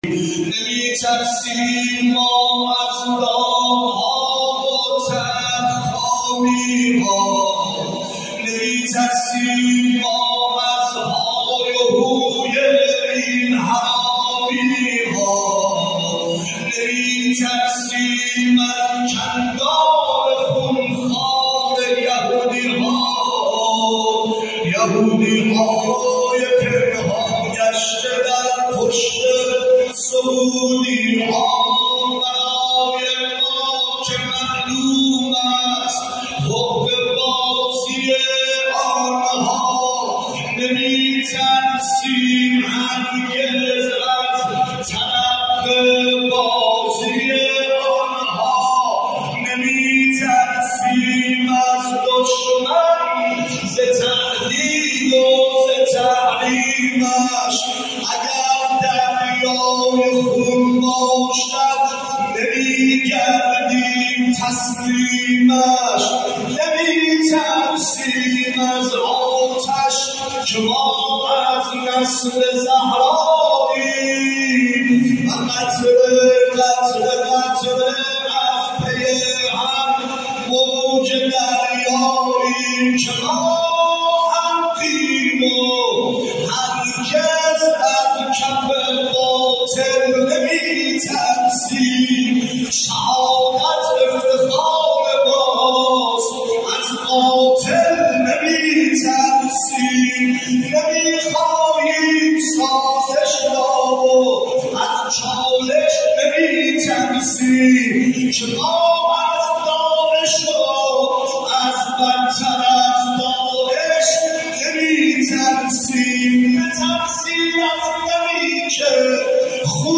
رجزخوانی خواننده سلام فرمانده علیه اغتشاشگران